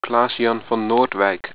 Pronounced